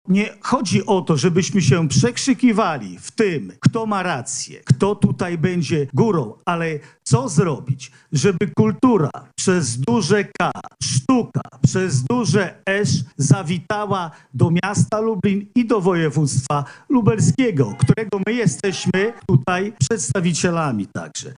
-mówi Jarosław Stawiarski, marszałek województwa lubelskiego.